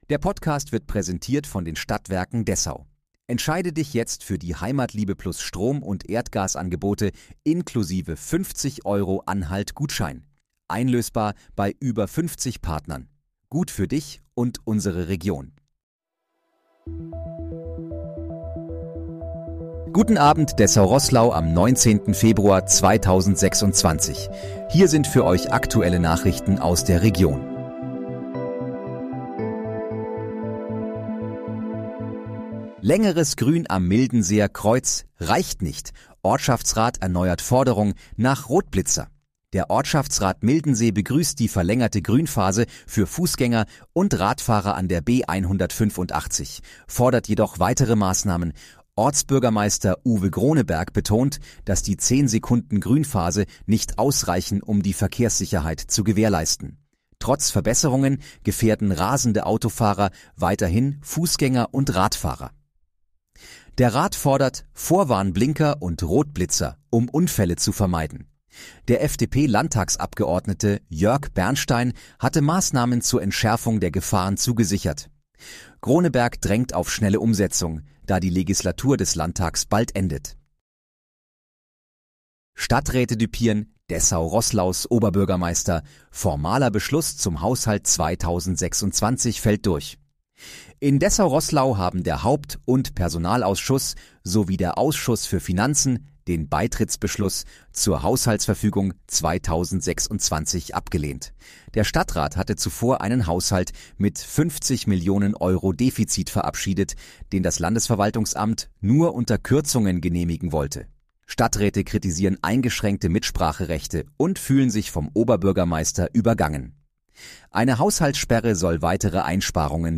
Guten Abend, Dessau-Roßlau: Aktuelle Nachrichten vom 19.02.2026, erstellt mit KI-Unterstützung
Nachrichten